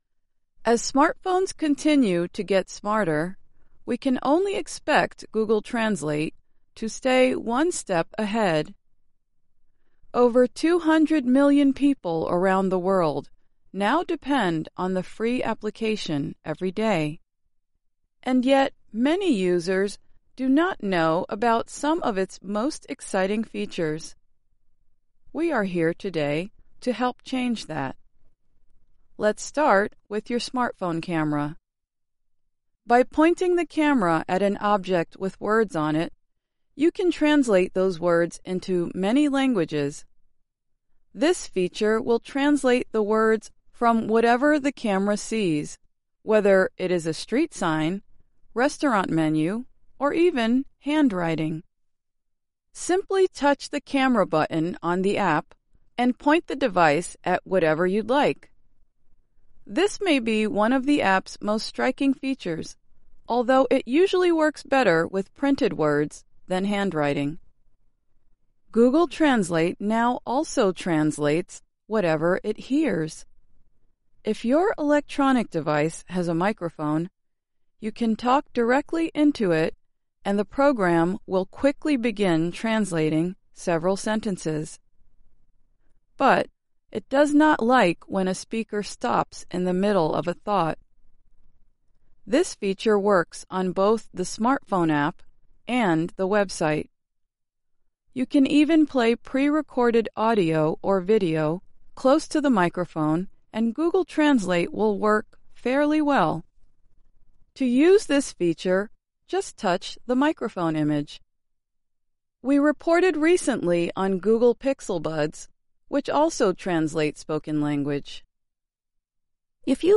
慢速英语:谷歌翻译会做哪些你不知道的事情?